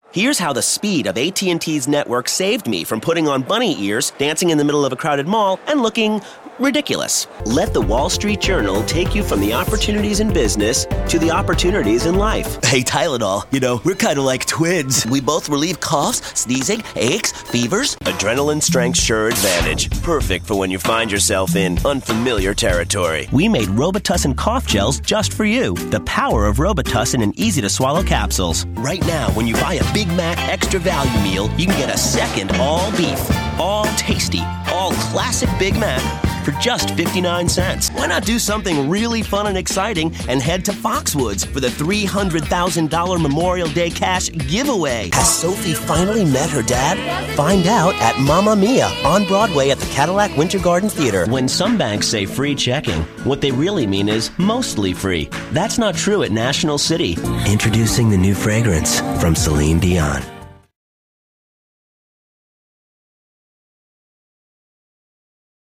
commercial : men